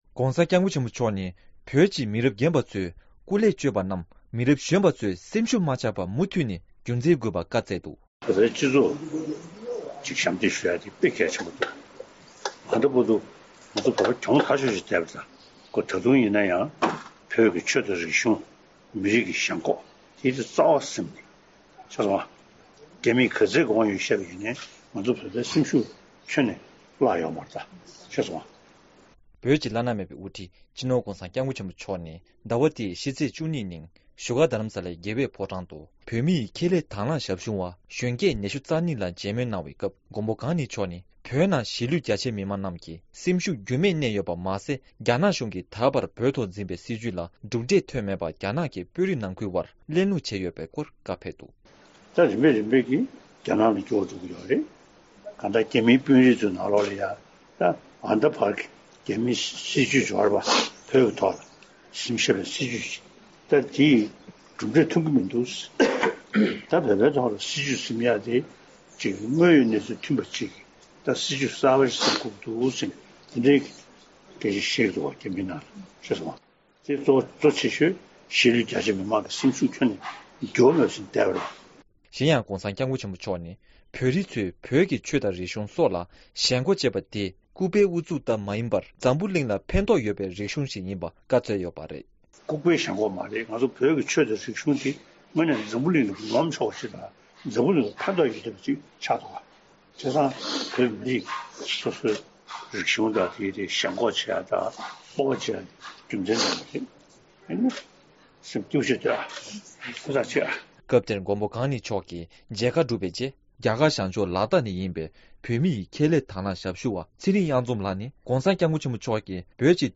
༧གོང་ས་མཆོག་གིས་བོད་མིའི་ཁྱད་ལས་དྭང་བླངས་ཞབས་ཞུ་བ་ཁག་གཅིག་ལ་མཇལ་ཁའི་སྐབས། ༢༠༡༩།༧།༡༢ ༧གོང་ས་མཆོག་གིས་བོད་མིའི་ཁྱད་ལས་དྭང་བླངས་ཞབས་ཞུ་བ་ཁག་གཅིག་ལ་མཇལ་ཁའི་སྐབས། ༢༠༡༩།༧།༡༢
སྒྲ་ལྡན་གསར་འགྱུར། སྒྲ་ཕབ་ལེན།